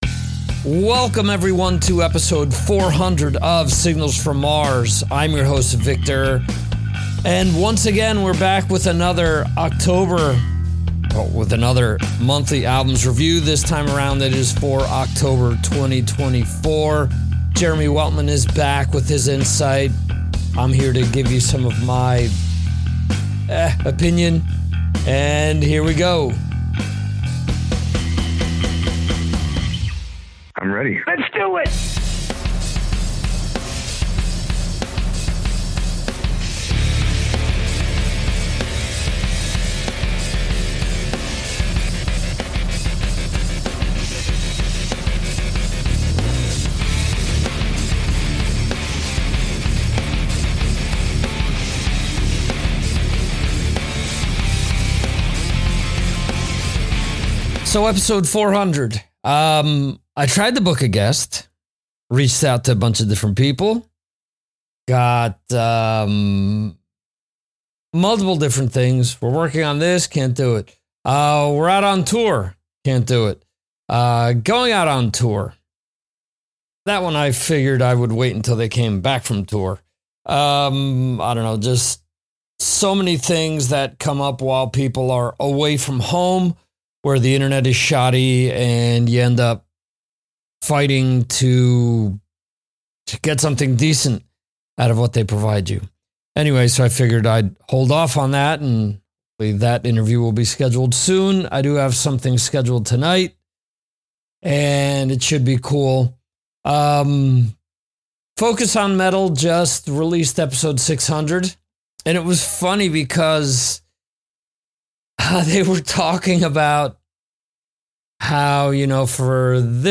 All types of hard rock and metal interviews and music discussions since 2009.